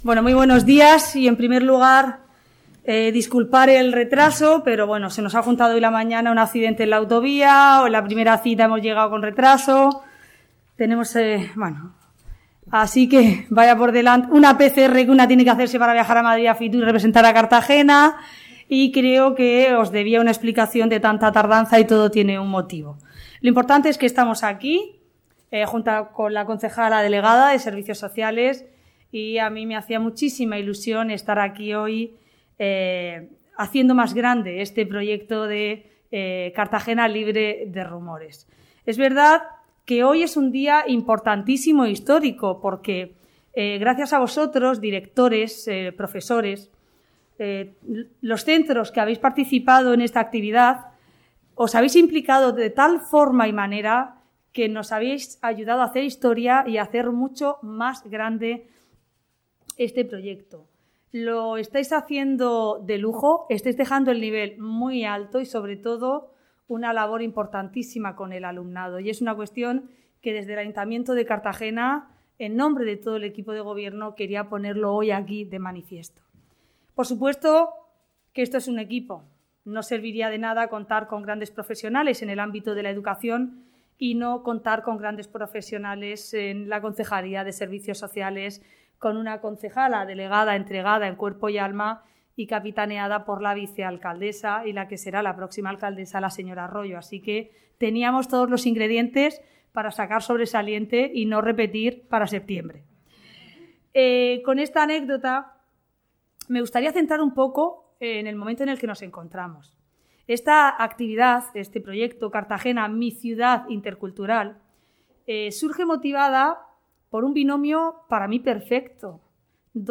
Audio: Intervenci�n de la alcaldesa Ana Bel�n Castej�n. (MP3 - 7,91 MB)